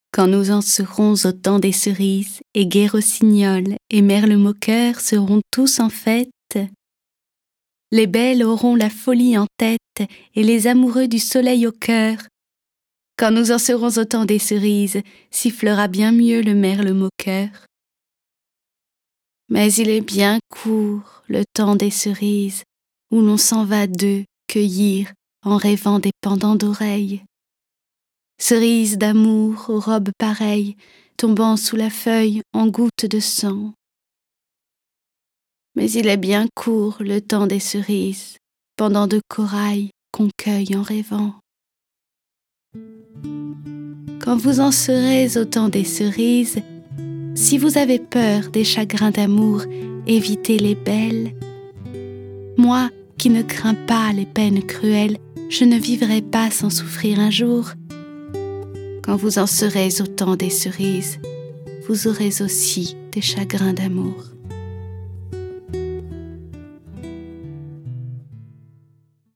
Le récit et les dialogues sont illustrés avec les musiques de Beethoven, Borodine, Chopin, Corelli, Debussy, Dvorak, Grieg, Mozart, Pergolèse, Rimsky-Korsakov, Schubert, Tchaïkovski, Telemann et Vivaldi.